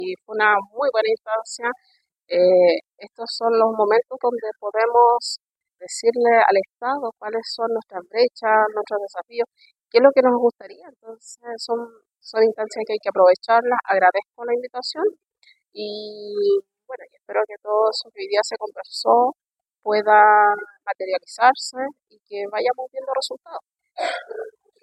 El Museo Interactivo de Osorno fue el lugar de encuentro para desarrollar la Segunda Mesa Regional de Energía Más Mujeres y Capital Humano, una iniciativa desarrollada en conjunto entre el Ministerio de Energía y la Agencia de Sostenibilidad Energética.